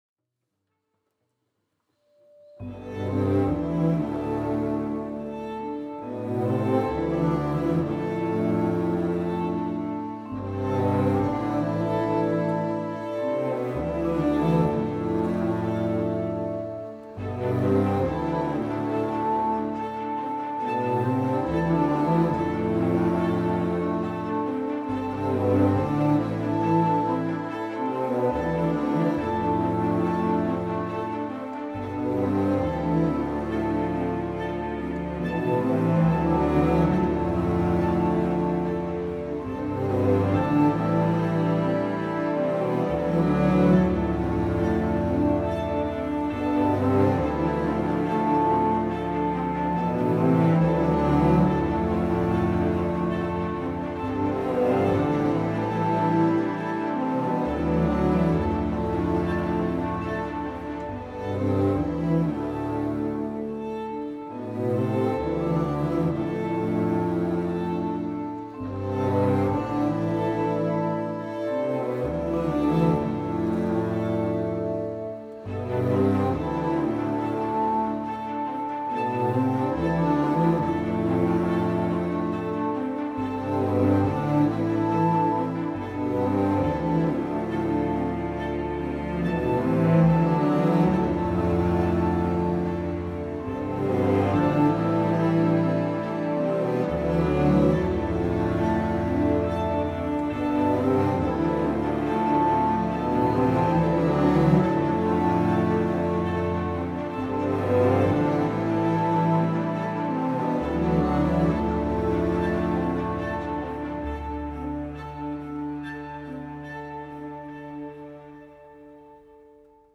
I recorded the cello line I'd heard clearly in my head.